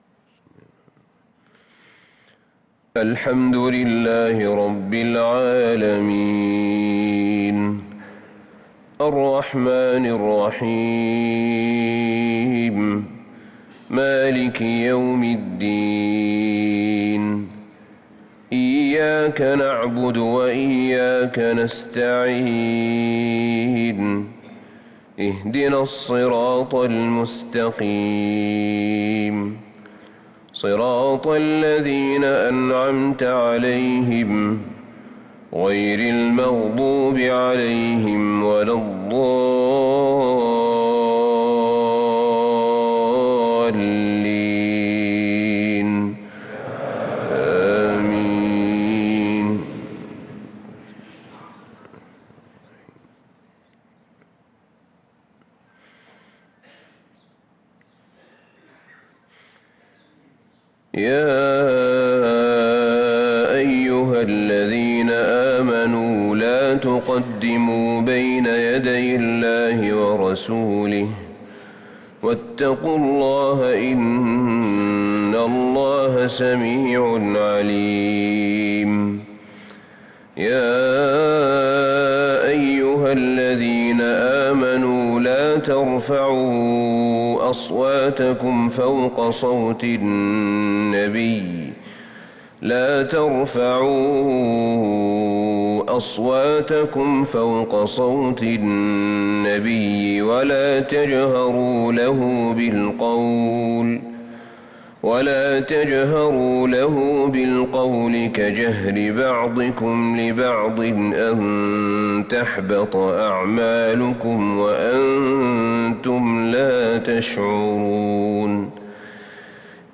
صلاة الفجر
تِلَاوَات الْحَرَمَيْن .